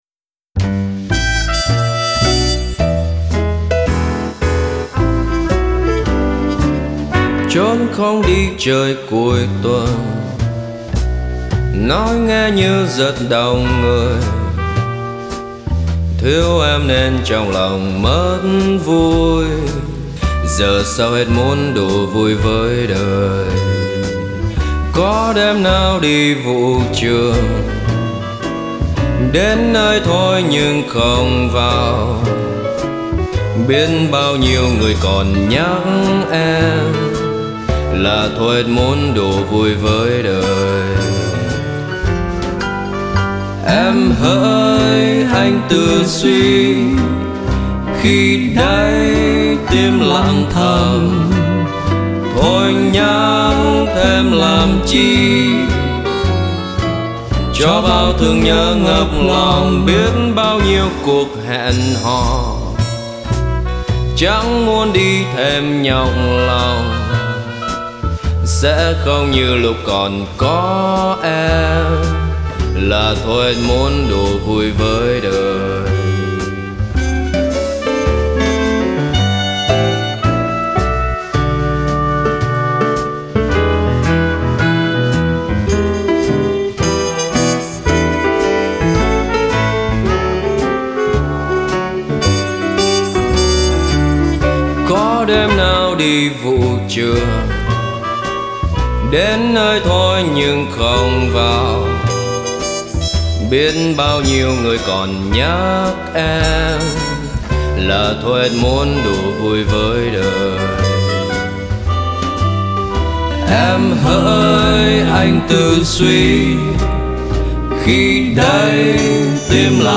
Nay bạn hòa âm, chậm đi một tí, rã rời hơn một tí.
Giọng anh ngầy ngật như đang say.